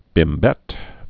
(bĭm-bĕt)